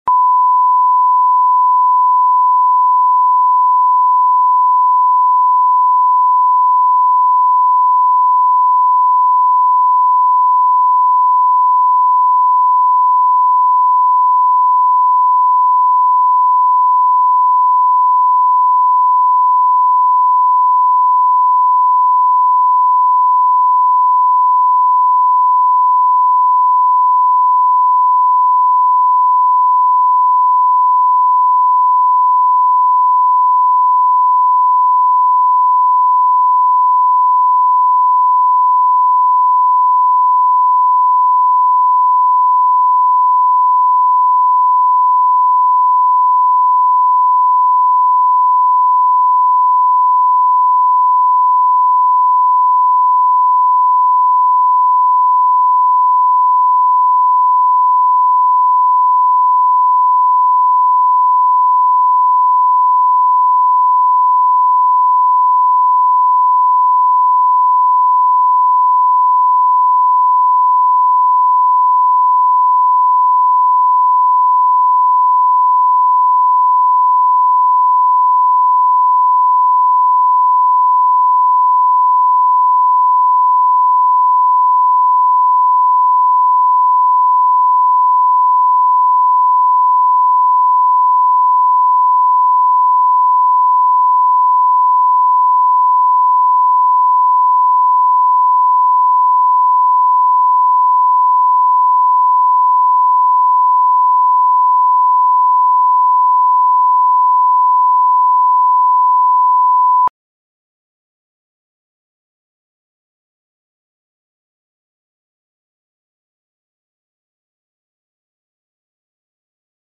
Аудиокнига С детьми и без детей | Библиотека аудиокниг